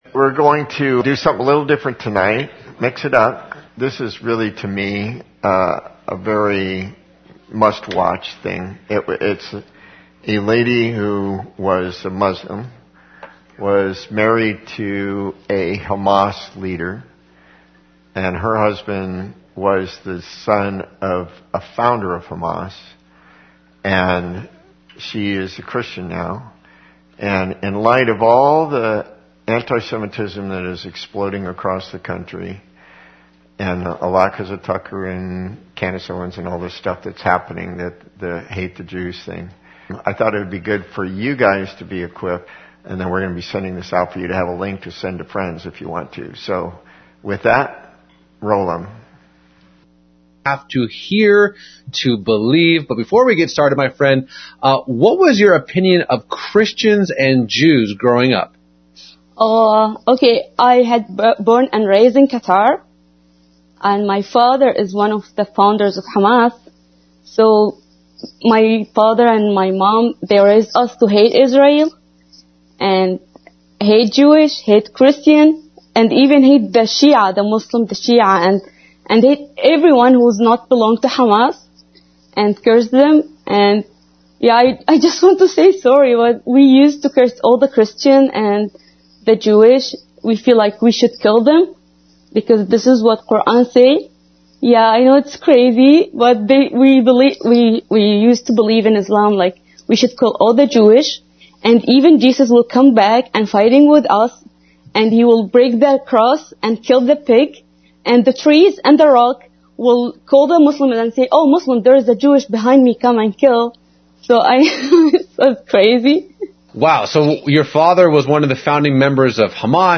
About a 22 minute testimony by a former Muslim, Hamas woman on how she came to faith in Jesus is before a study in Matthew 19:16-29